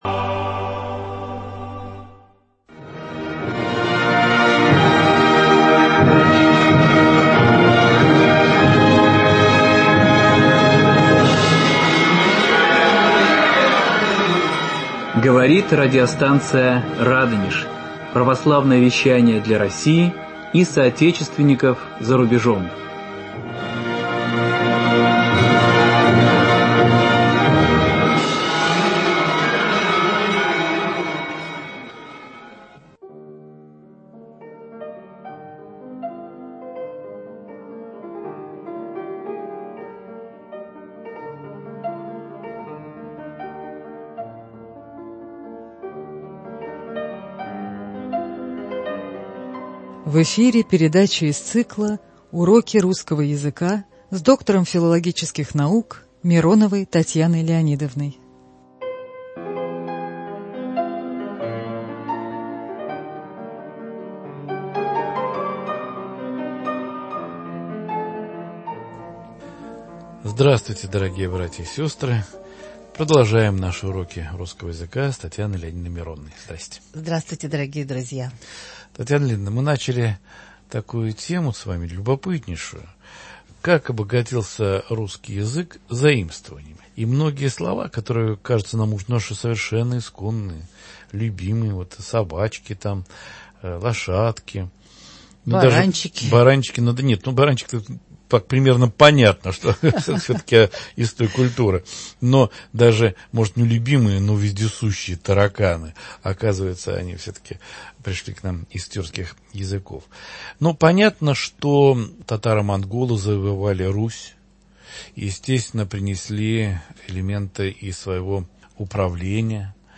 Доктор филологических наук
в беседе